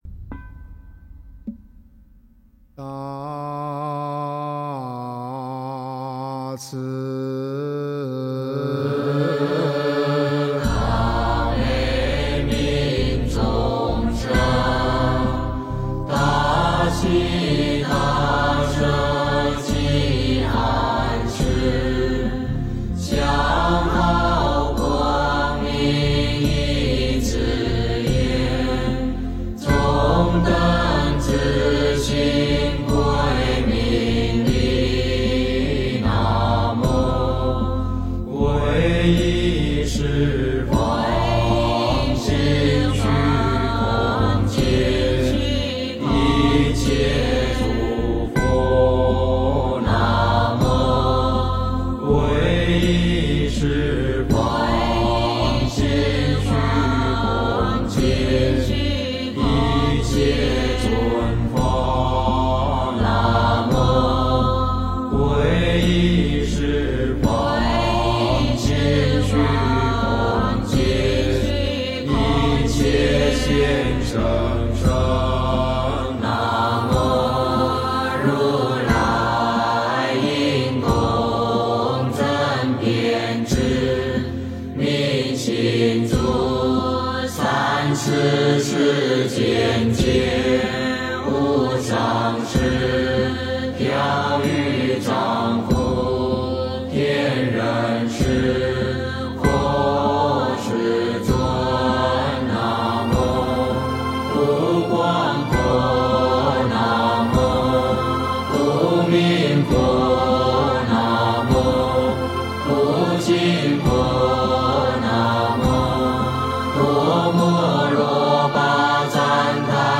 八十八佛大忏悔文 - 诵经 - 云佛论坛